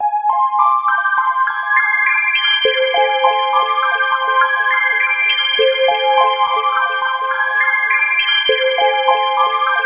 Index of /90_sSampleCDs/USB Soundscan vol.13 - Ethereal Atmosphere [AKAI] 1CD/Partition B/01-SEQ PAD B
SEQ PAD07.-L.wav